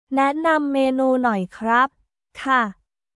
ネー ナム メーヌー ノーイ クラップ/カー